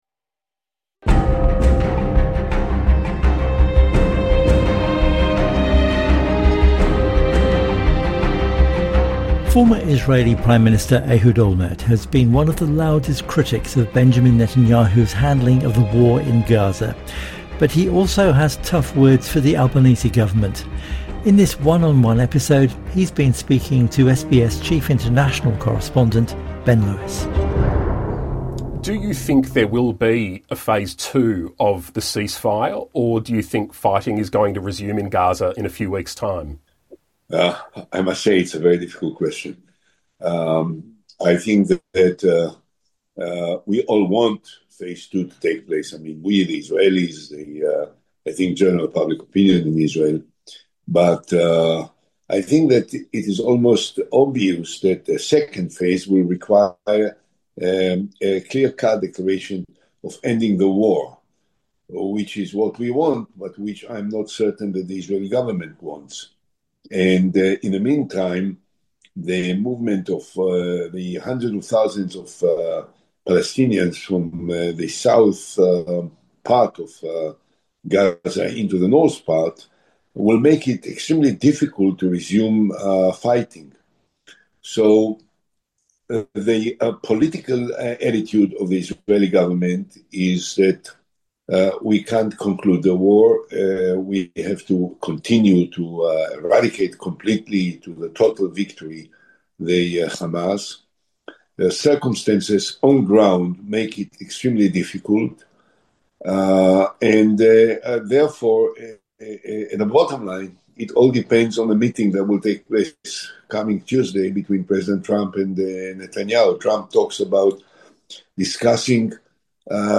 INTERVIEW: Former Israeli Prime Minister Ehud Olmert